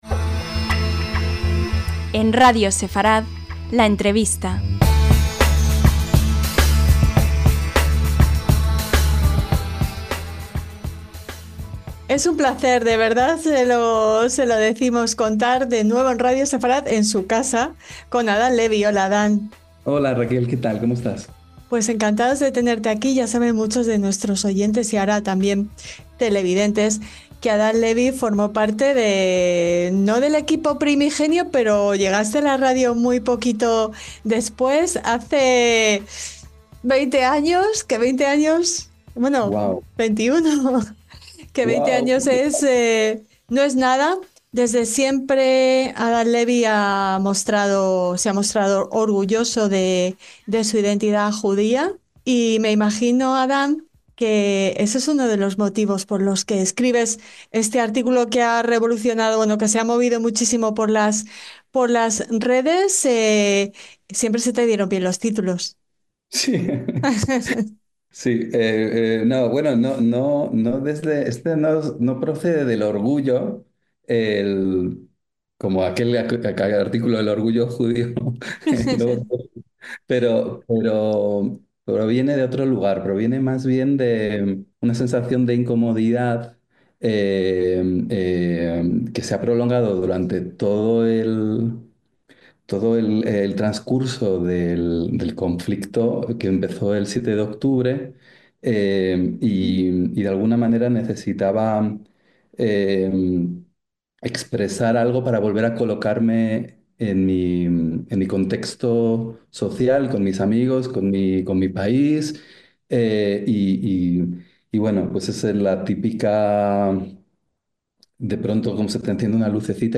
LA ENTREVISTA - ¡Qué título, y qué verdad, ¿no creen?!